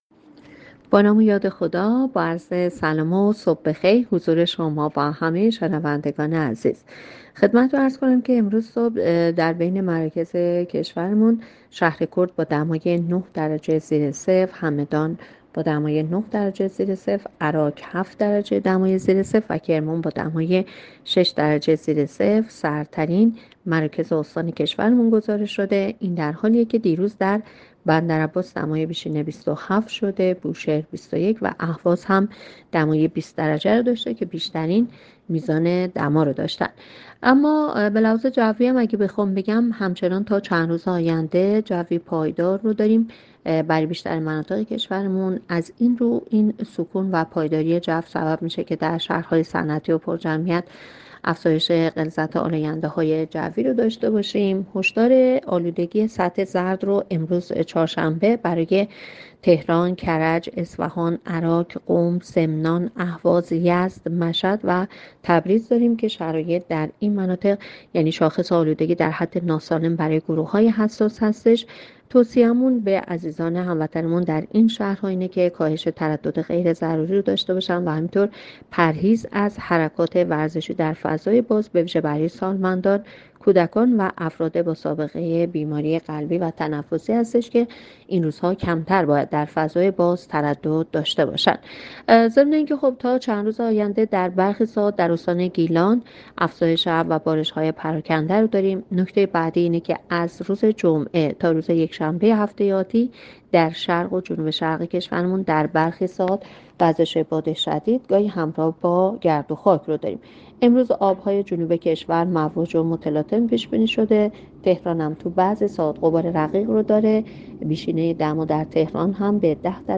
گزارش رادیو اینترنتی پایگاه‌ خبری از آخرین وضعیت آب‌وهوای ۱۴ آذر؛